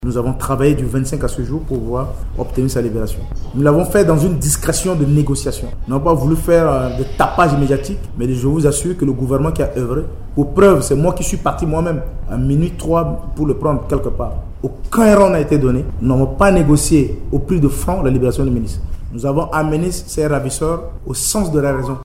Sans préciser l’endroit de la libération, le ministre centrafricain de la sécurité publique, Nicaise Samedi Karnou, affirme qu'il s'agit d’une libération sans condition.